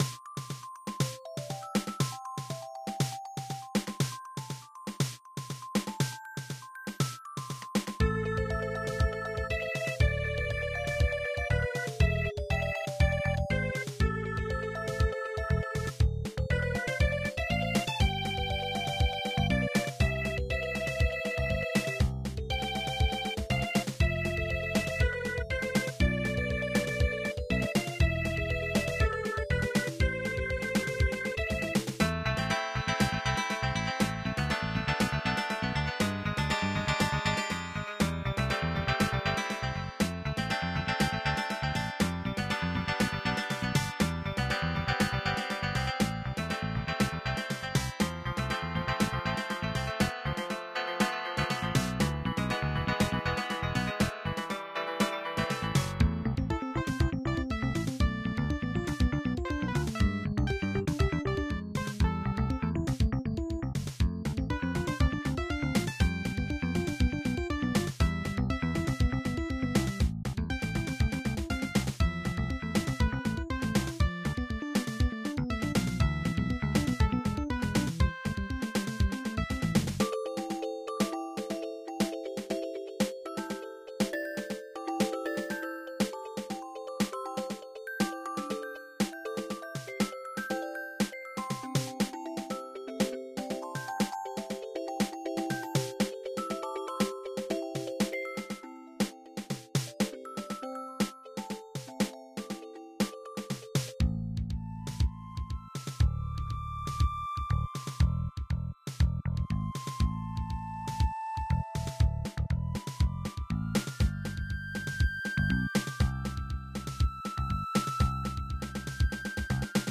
Update musics with the dos/win soundfont